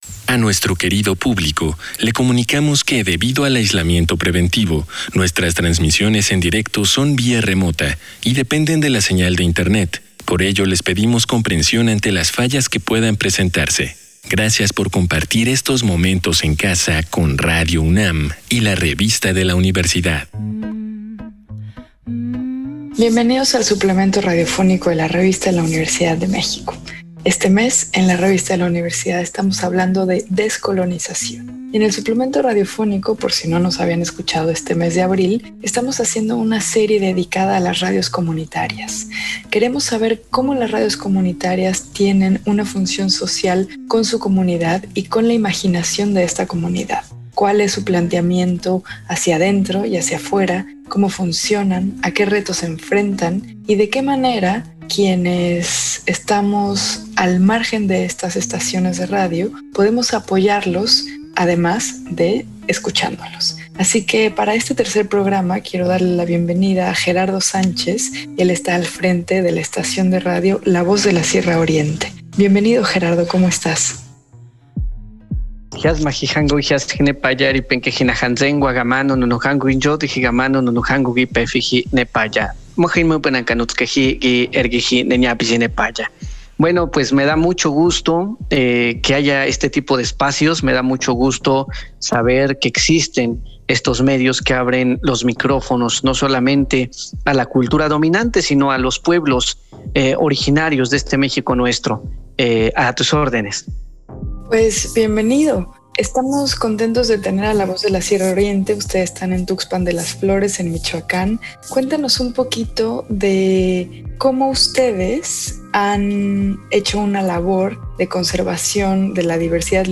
Fue transmitido el jueves 8 de abril de 2021 por el 96.1 FM.